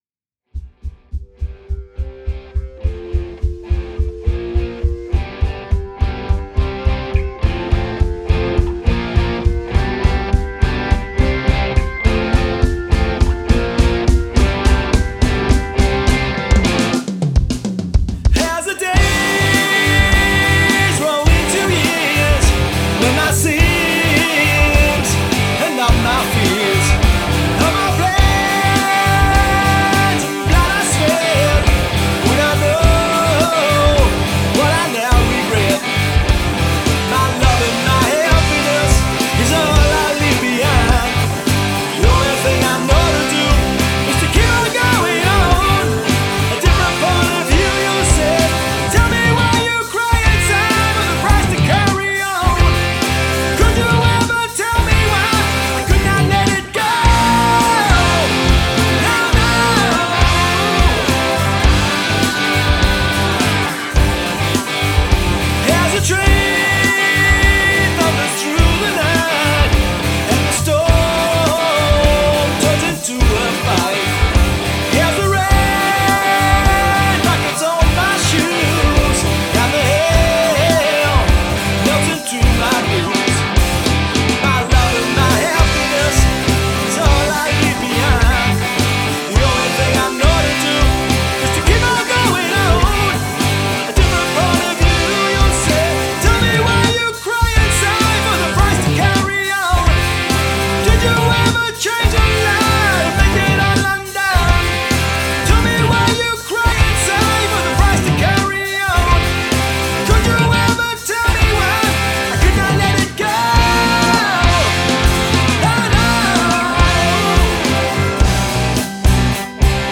Guitars, Vocals & Bass
Hammond Organ, Wurlitzer & Fender Rhodes
blues-edged rock